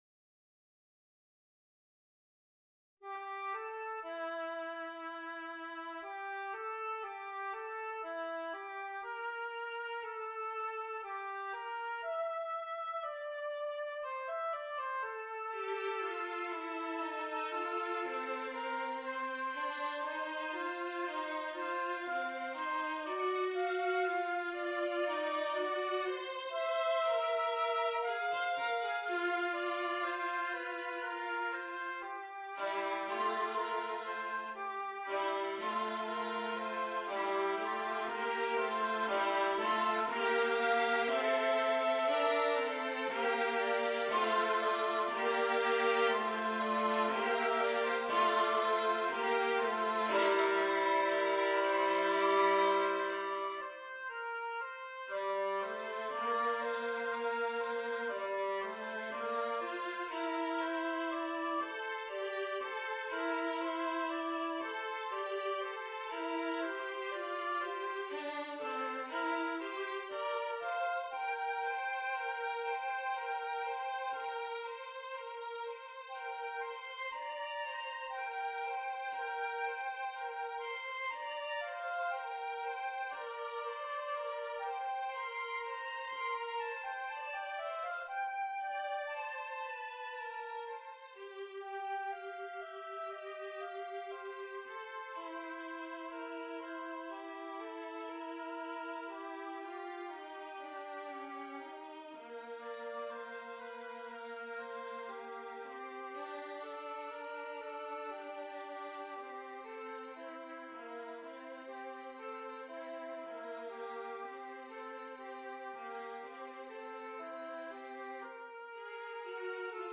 Oboe, Violin